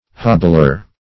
Hobbler \Hob"bler\, n.